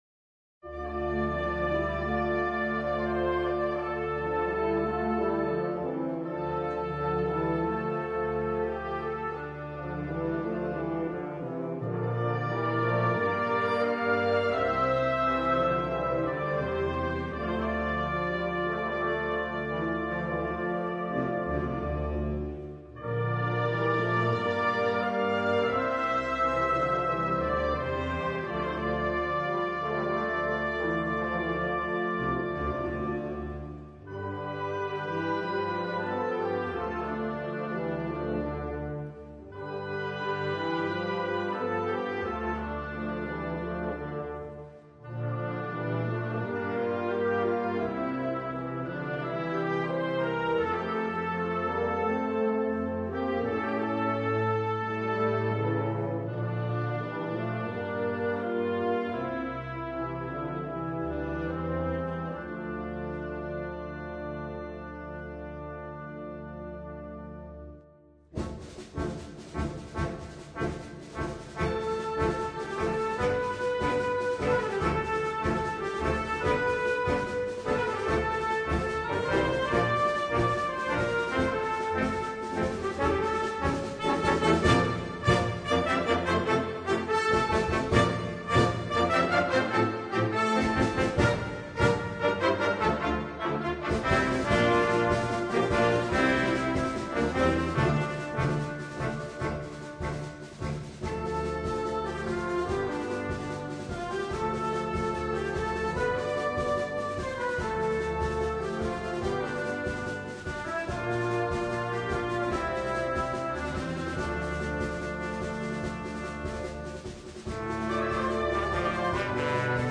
Brano per banda giovanile ad organico variabile